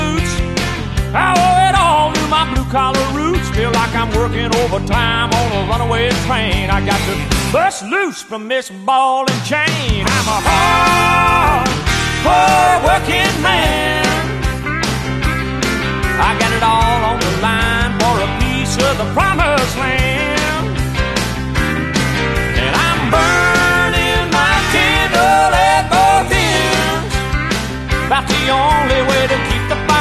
Skid steer work with the sound effects free download